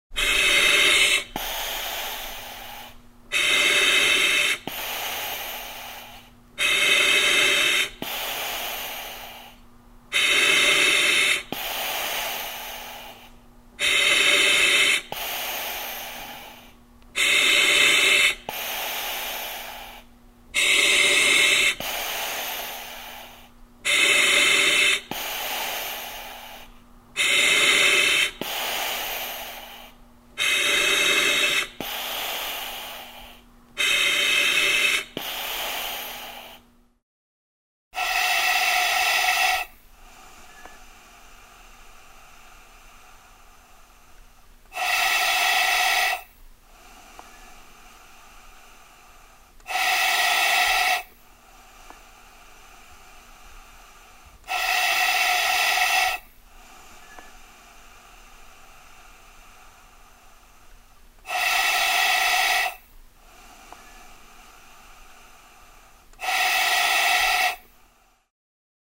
Маска для дыхательного аппарата